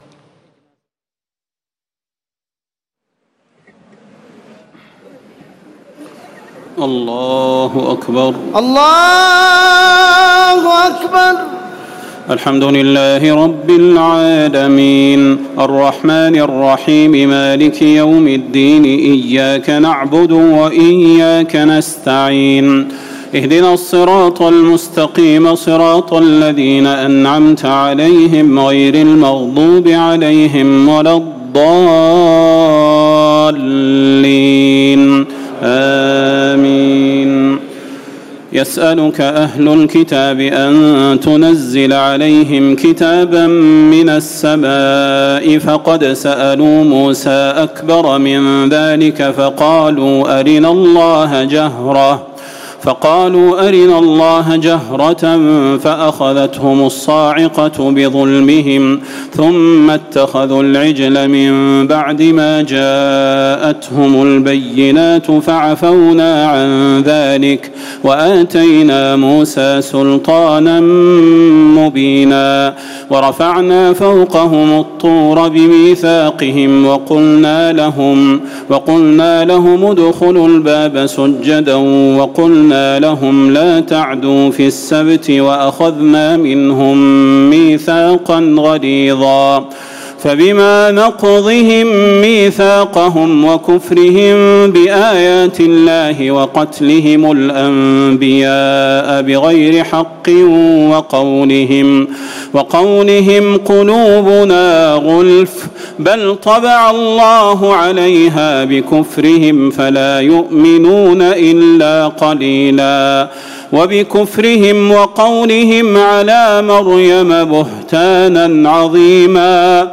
تراويح الليلة الخامسة رمضان 1439هـ من سورتي النساء (153-176) و المائدة (1-40) Taraweeh 5 st night Ramadan 1439H from Surah An-Nisaa and AlMa'idah > تراويح الحرم النبوي عام 1439 🕌 > التراويح - تلاوات الحرمين